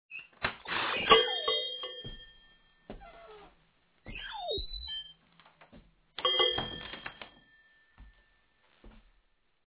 door5.mp3